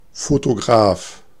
Ääntäminen
Vaihtoehtoiset kirjoitusmuodot Fotograf Synonyymit Lichtbildner Ääntäminen Tuntematon aksentti: IPA: [fotoˈɡʀaːf] Haettu sana löytyi näillä lähdekielillä: saksa Käännös 1. φωτογράφος {m} (fotográfos) Artikkeli: der .